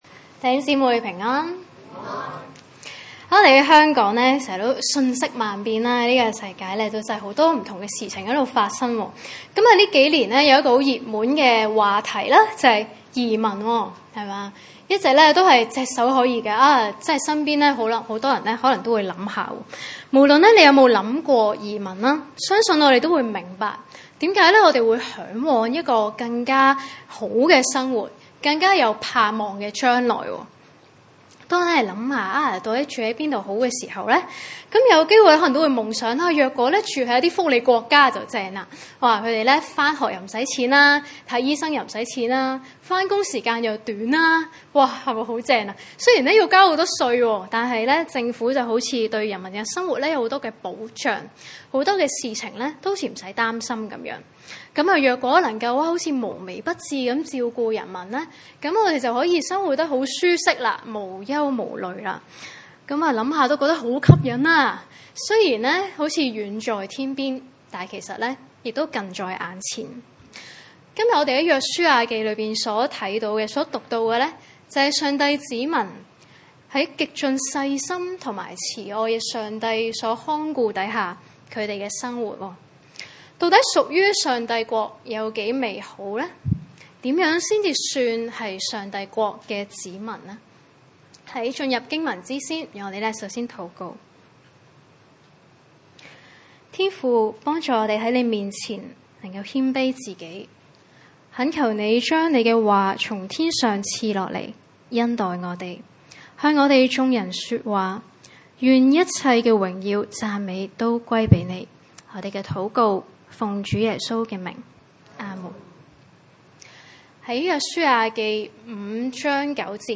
書5:9-12 崇拜類別: 主日午堂崇拜 9.耶和華對約書亞說：我今日將埃及的羞辱從你們身上滾去了。